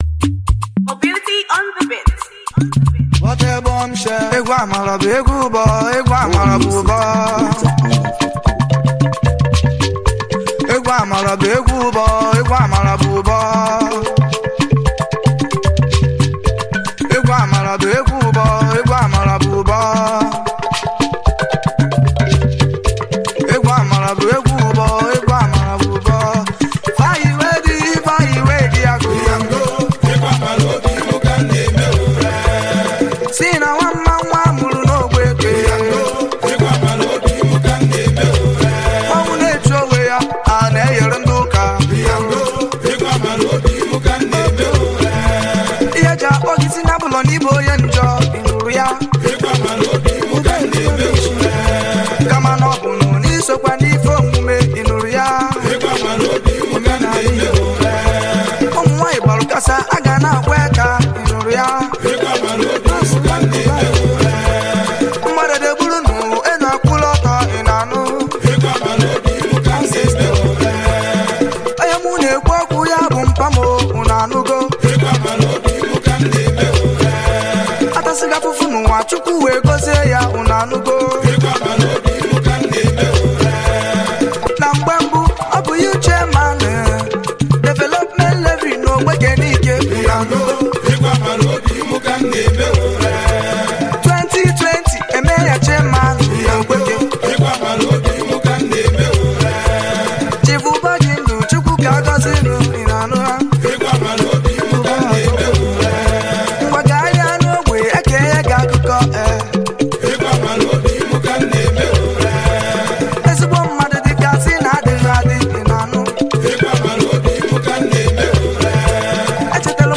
Home » Bongo